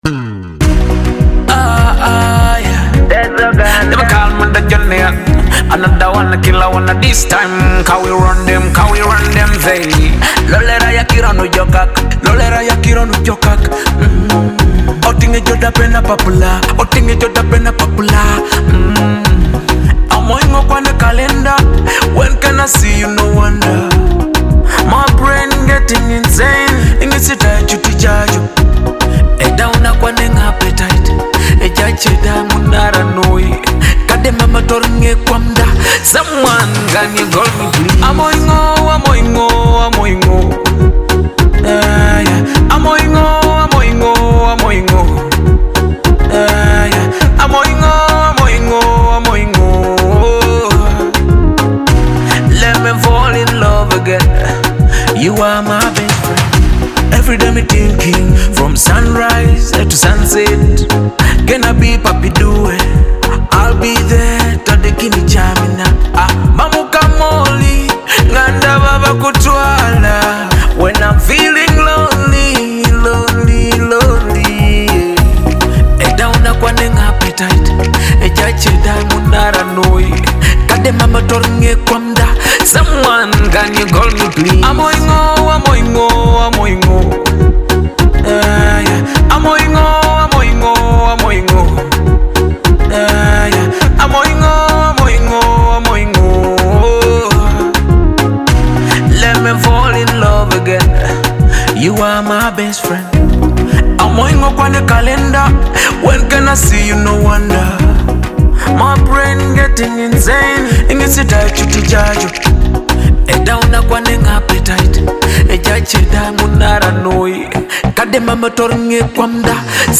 Afrobeat-Dancehall fusion
With its vibrant Teso-inspired rhythm and feel-good lyrics